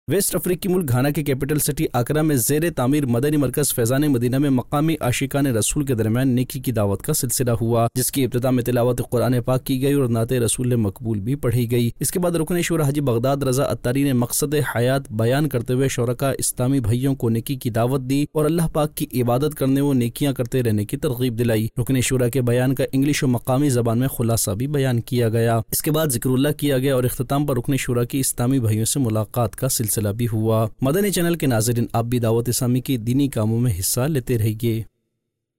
News Clips Urdu - 04 August 2023 - Zair e Tameer Madani Markaz Faizan e Madina Main Aashiqan e Rasool Main Neki Ki Dawat Aug 11, 2023 MP3 MP4 MP3 Share نیوز کلپس اردو - 04 اگست 2023 - زیرِ تعمیر مدنی مرکز فیضان مدینہ میں عاشقانِ رسول میں نیکی کی دعوت